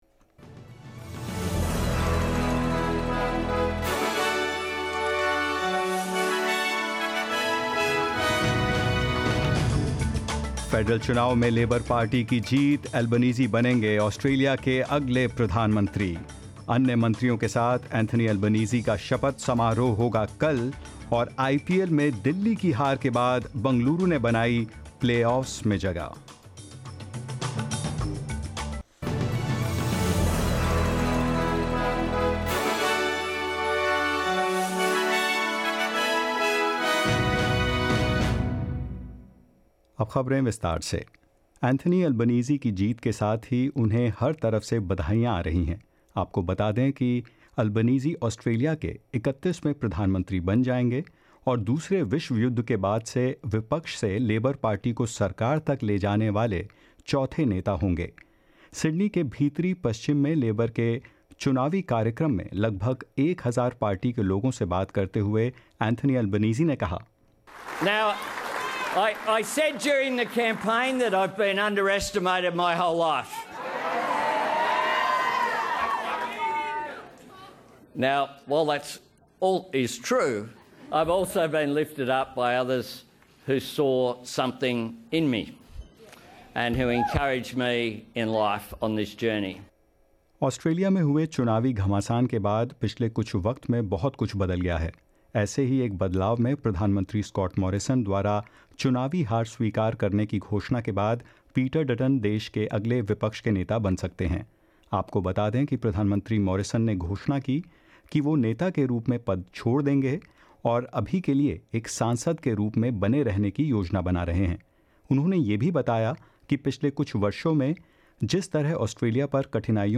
In this latest SBS Hindi bulletin: Labor leader Anthony Albanese to become the 31st Prime Minister of Australia; Scott Morrison to step down as leader of the Liberal Party and more